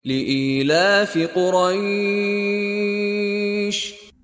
Contoh Bacaan dari Sheikh Mishary Rashid Al-Afasy
Mad Lin : Bacaan lunak atau lembut dengan intonasi bacaan yang lunak dan lembut.